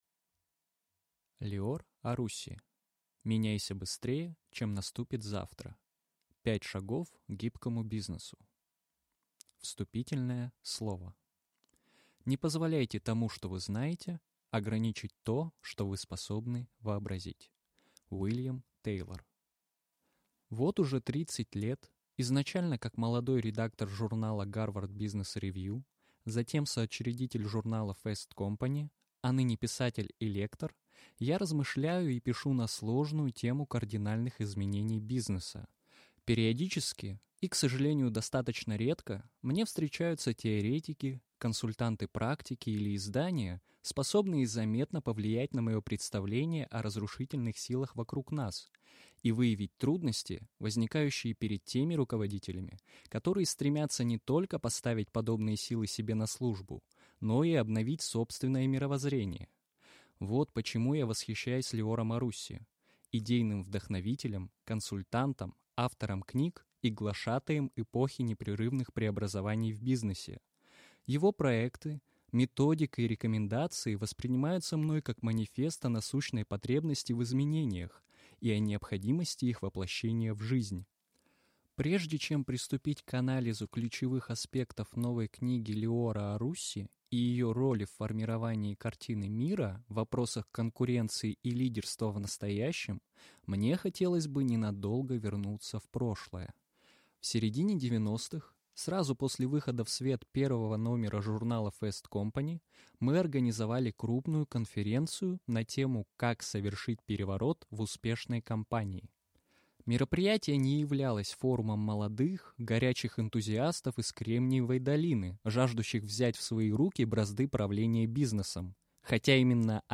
Aудиокнига Меняйся быстрее, чем наступит завтра. 5 шагов к созданию гибкого бизнеса